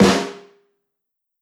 Medicated Snare 10.wav